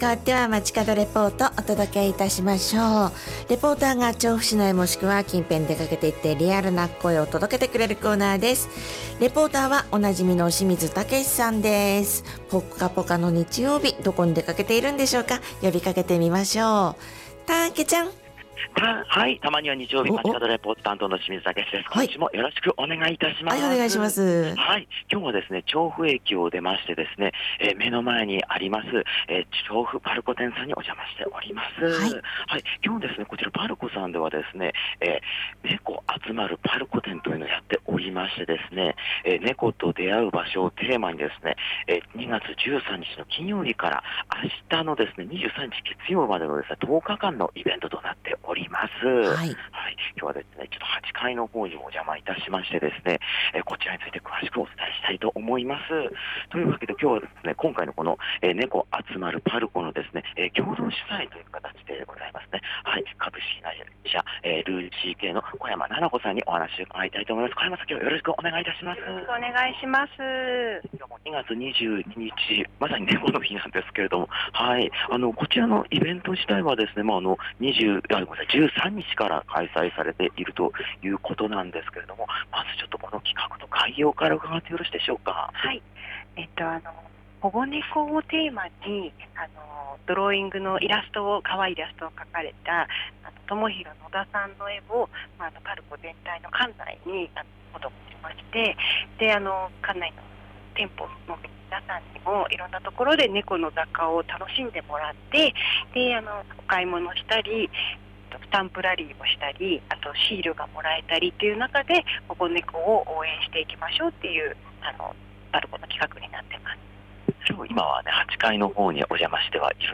暖かい気候となった空の下からお届けした本日の街角レポートは、調布PARCOさんで開催中の「ねこ あつまる PARCO」の会場からお届けしました！